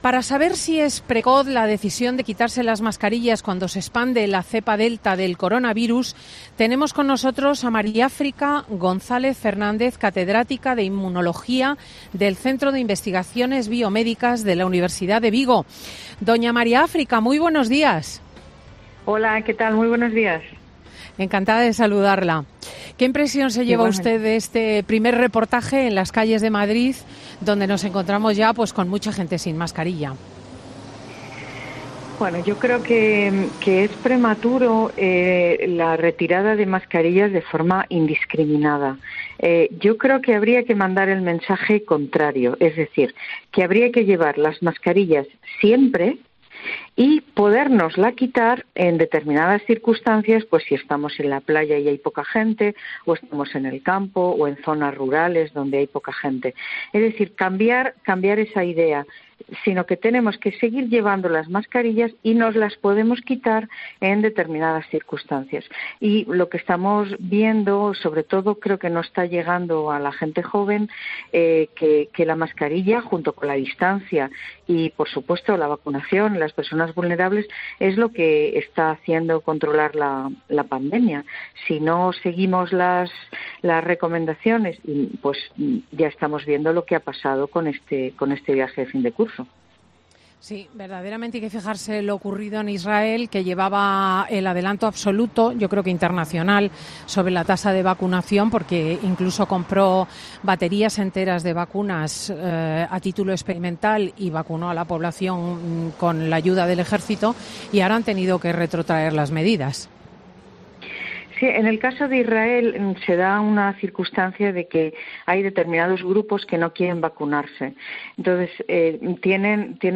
En palabras de la experta, que ha pasado por los micrófonos de Fin de Semana de COPE, es el momento de “mandar el mensaje contrario” en la lucha para frenar el coronavirus.